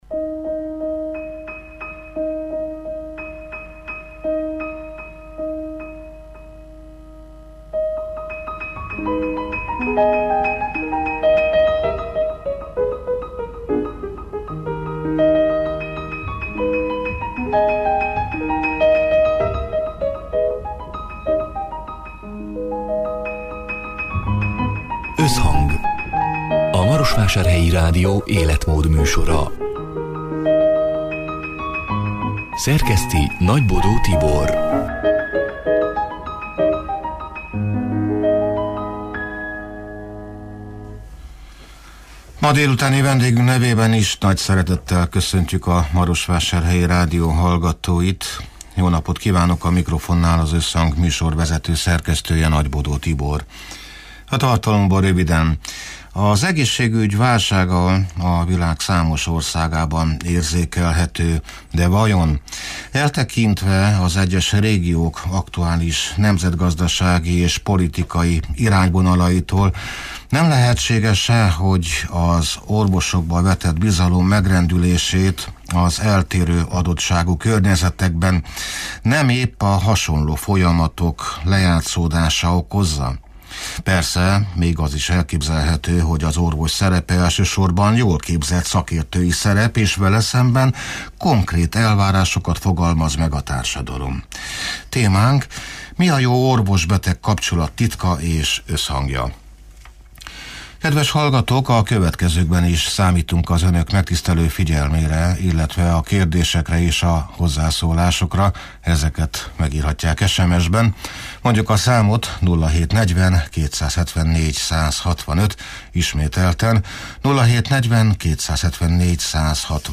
(elhangzott: 2024. november 27-én, szerdán délután hat órától élőben)